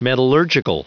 Prononciation du mot metallurgical en anglais (fichier audio)
Prononciation du mot : metallurgical
metallurgical.wav